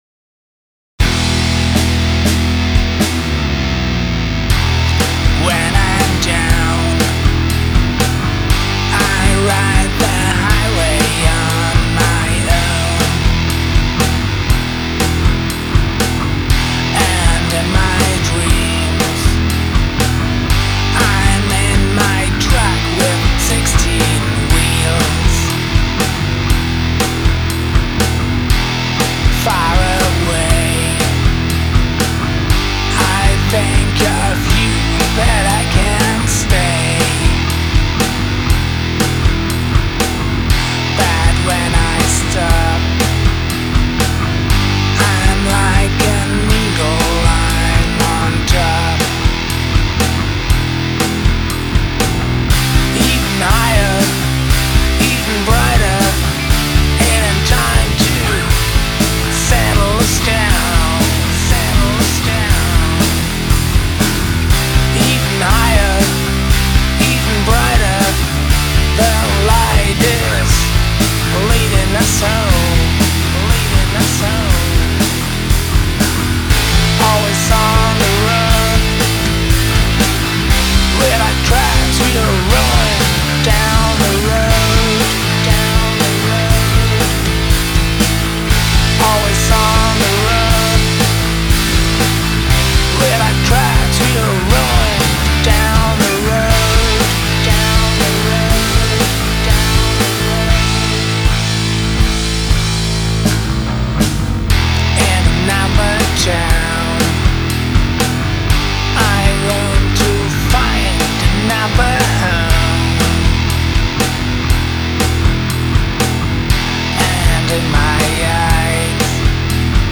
Ist mein Solo in Ordnung?
Dein Solo blieb mir gleich im Ohr hängen und das passiert nicht so oft, denn eingentlich bin ich auch ein "Bending-Boomer"...;) Erzähl mal mehr von dem Projekt, denn auch den Sänger find ich richtig gut, bist du das?